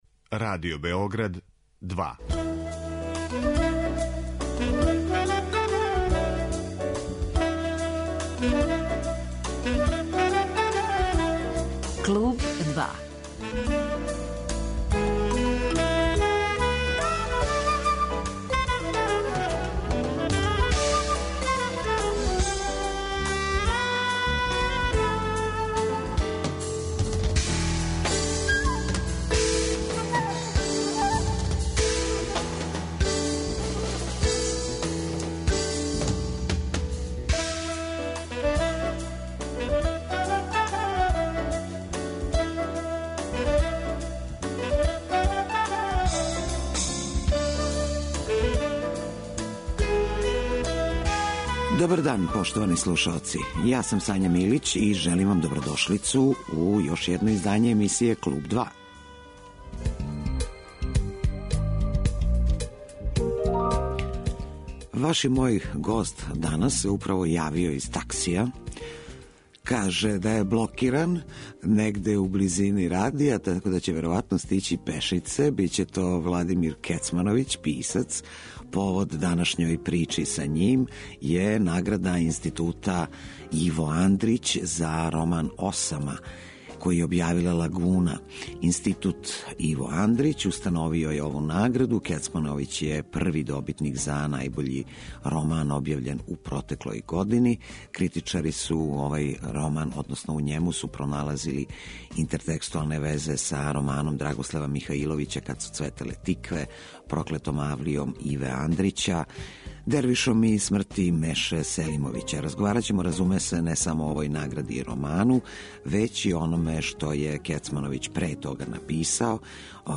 Гост емисије биће писац Владимир Кецмановић, први добитник награде Института 'Иво Андрић' за најбољи роман објављен у протеклој години.